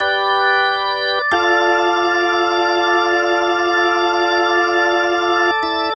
Organ 01.wav